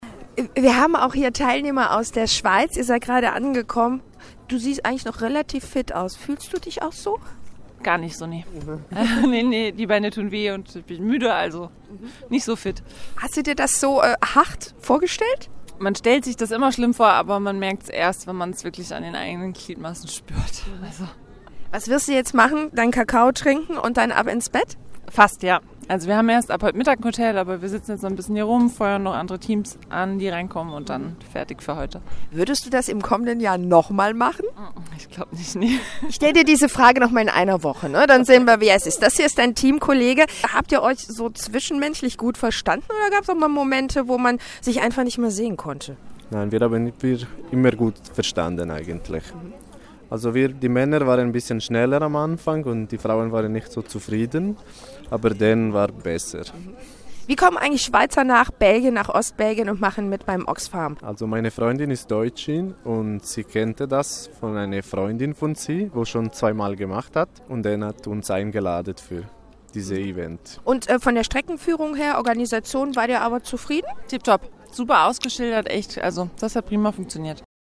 Eindrücke von der Oxfam Trailwalker Zielankunft